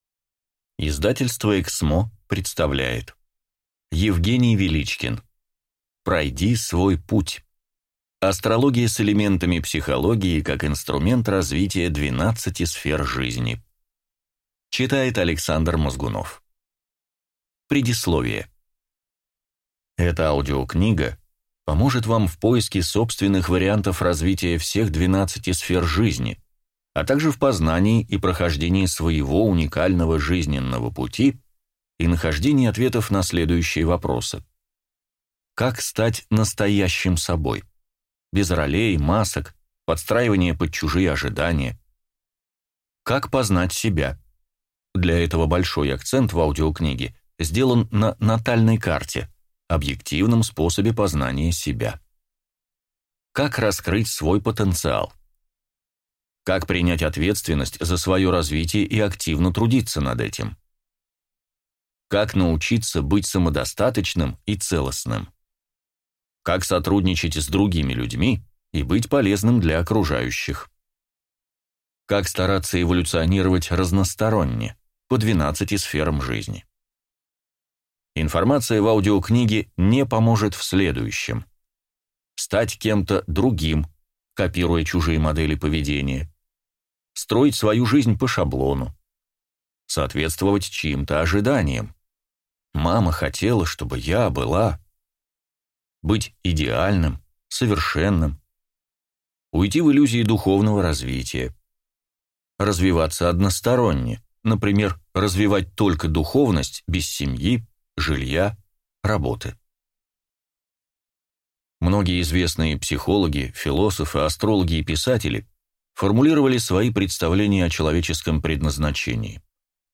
Аудиокнига Пройди свой путь. Астрология с элементами психологии как инструмент развития 12 сфер жизни | Библиотека аудиокниг